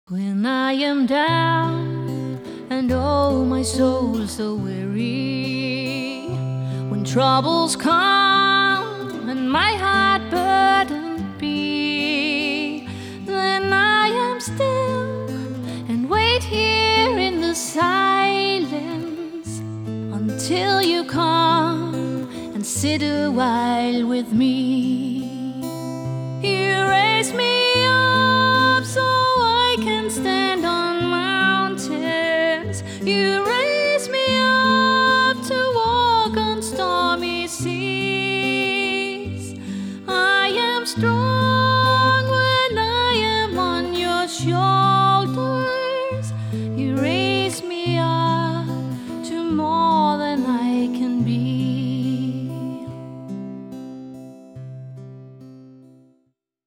Sangerinde og solomusiker (sang og guitar)
Smukt, akustisk og rørende.
Jeg spiller guitar til og medbringer selv udstyr.